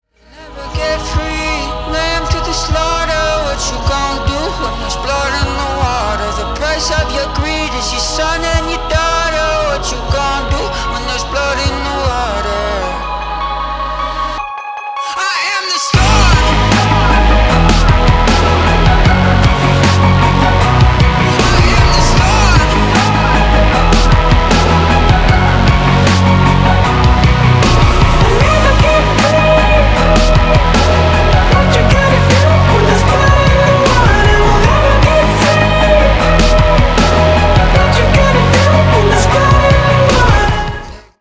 • Качество: 320, Stereo
громкие
женский голос
Alternative Rock
необычные
электронные